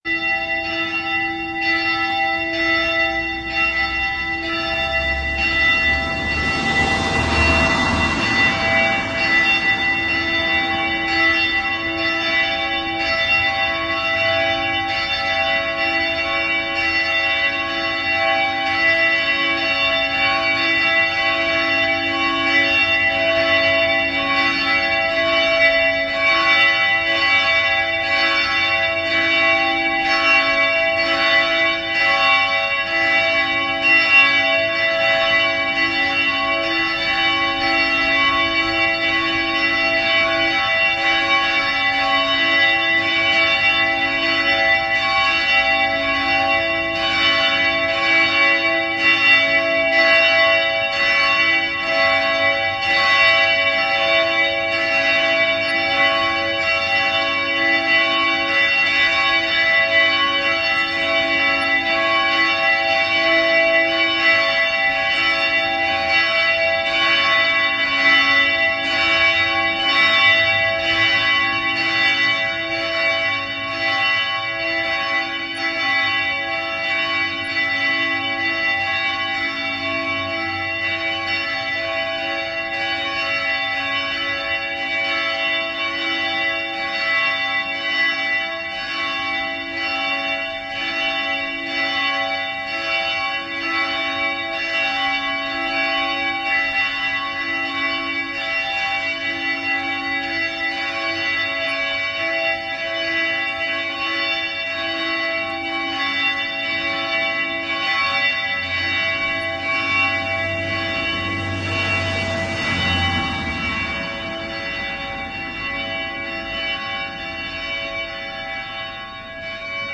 描述：A jetplain overheads, then church bell pealing.Audiotechnica BP4025立体声话筒，Shure FP24前置放大器，Olympus LS10录音机。
Tag: 飞机 教堂 现场录制